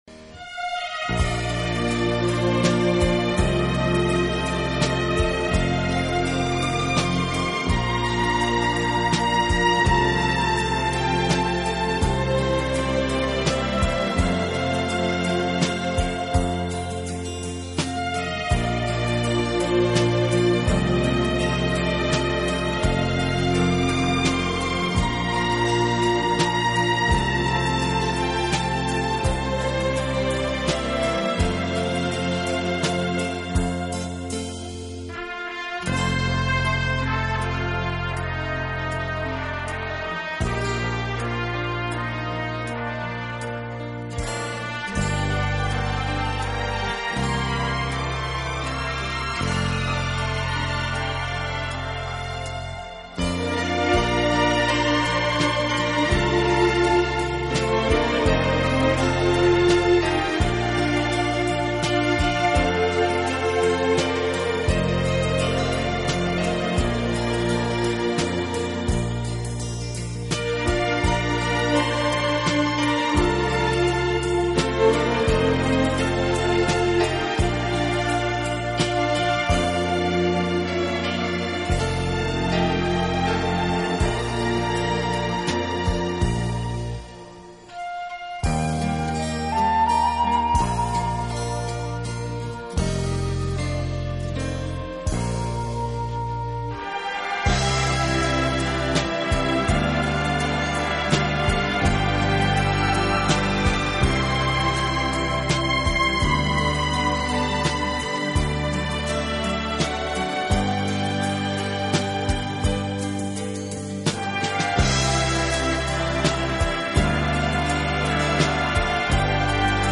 歌进行了重新的编排制作，既保留了爱尔兰民歌的轻快流畅，又不失大气。